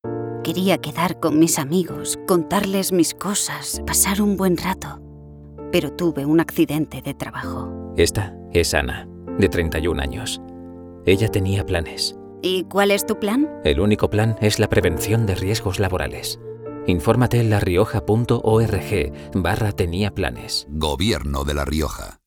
Cuñas radiofónicas
Cuña